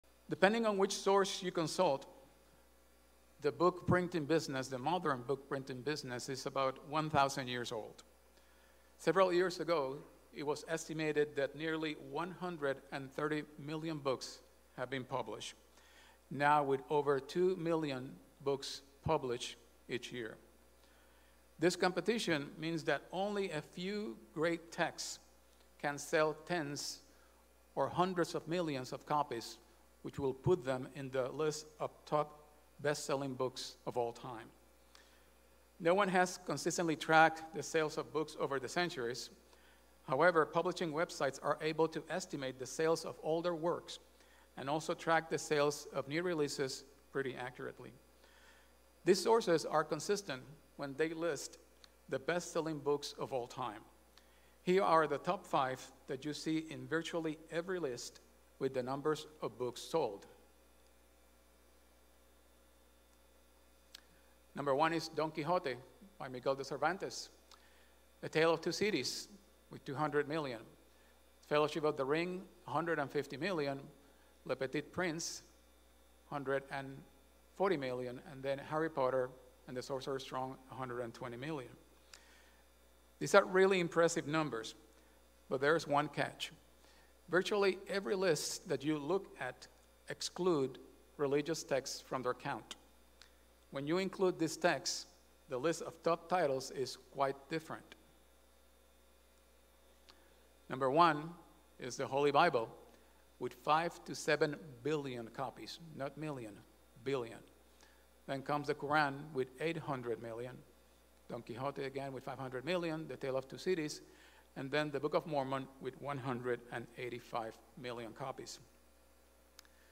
This sermon review keys points to God's Word: His Precious Gift to Us